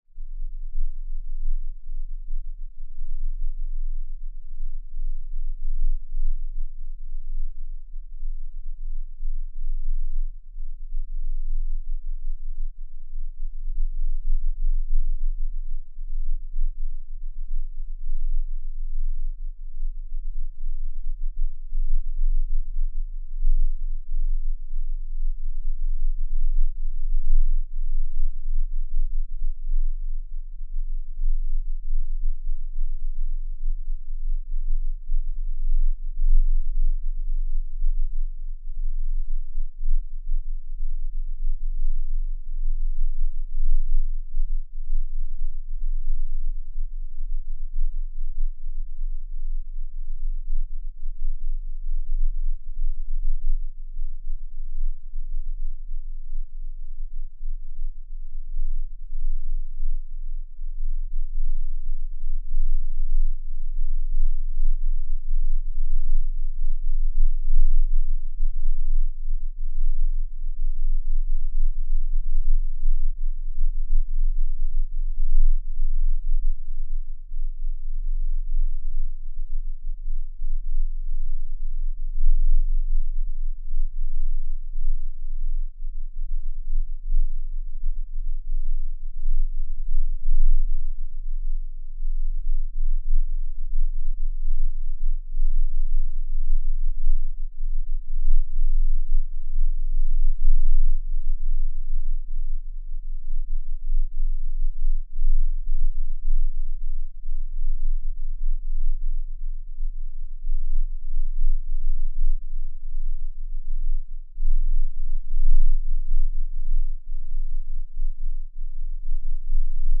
На этой странице собраны записи инфразвука — низкочастотных колебаний, находящихся за пределами обычного человеческого восприятия.
Звук инфразвук 19 Гц не каждый человек сможет услышать